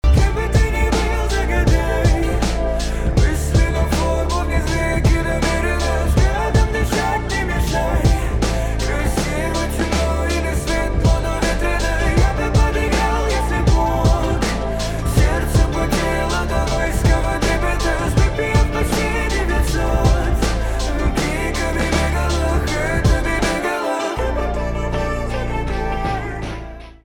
альтернатива
битовые , басы , чувственные , грустные